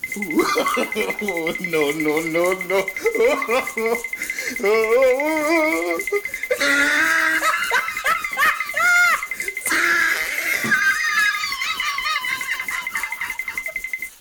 tickle1.wav